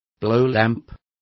Complete with pronunciation of the translation of blowlamps.